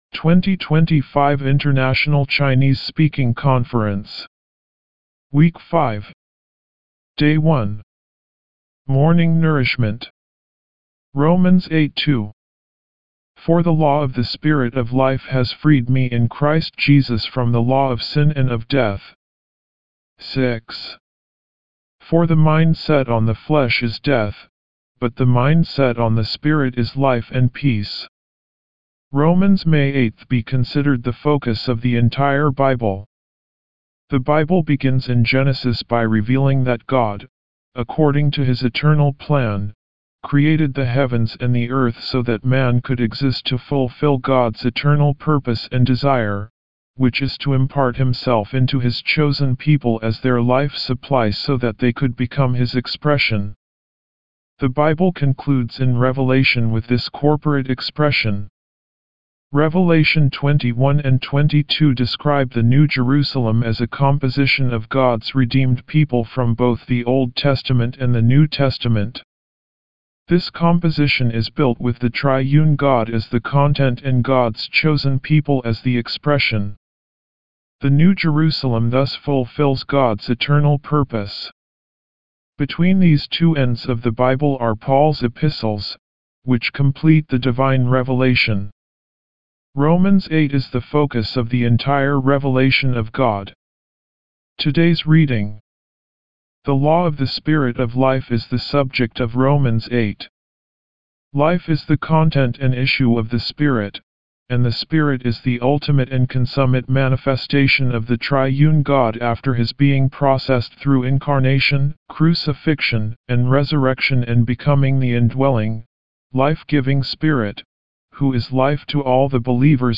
Morning Revival Recitation